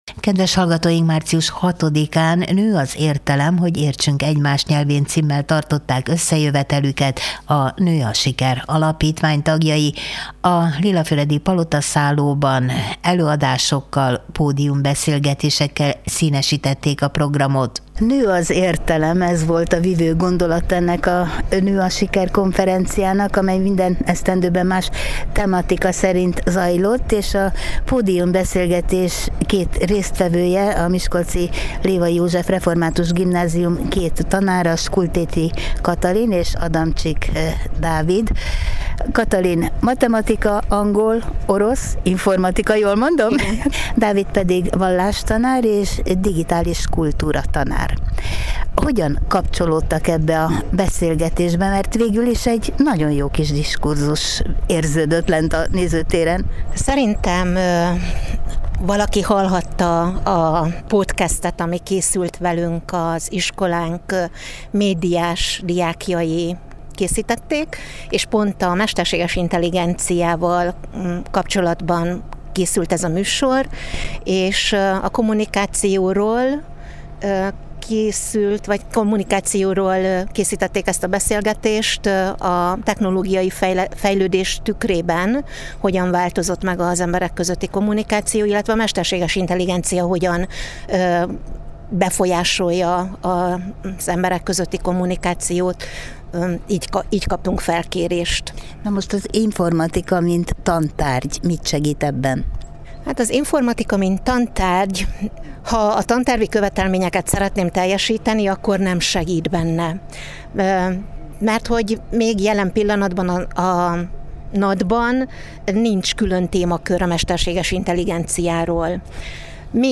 08-3_no_a_siker-podiumneszelgetes.mp3